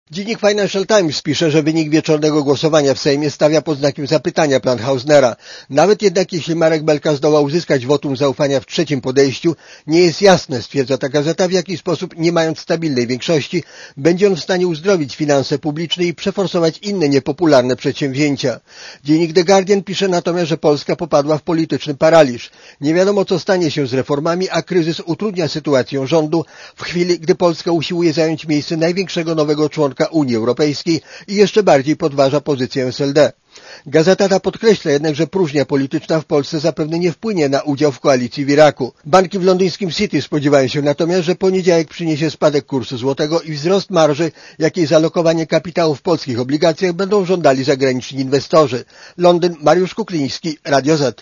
londyn_reakcje.mp3